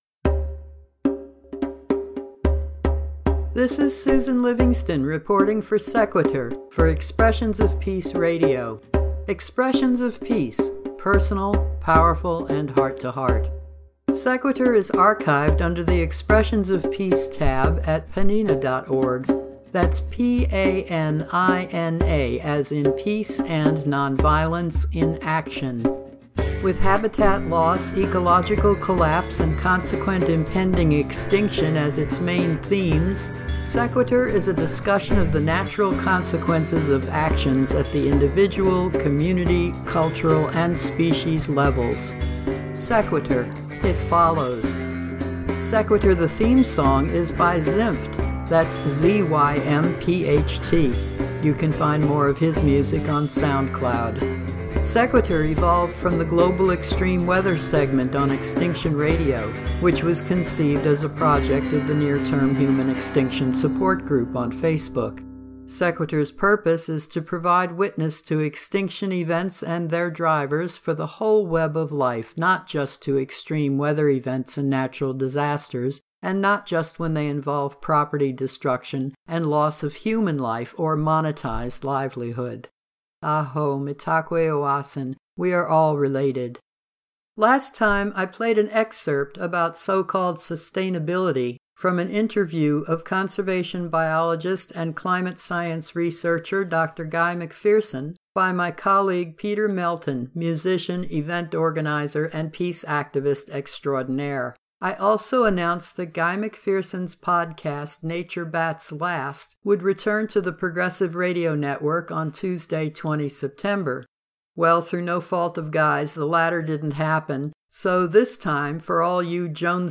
promotional announcements
a not-so-random assortment of news items